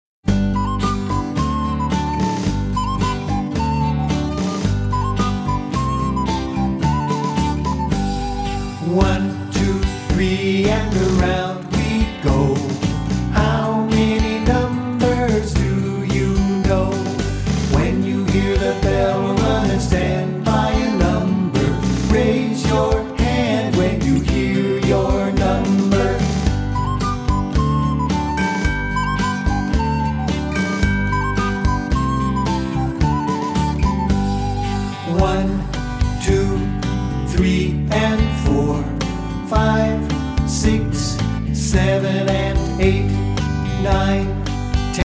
A counting song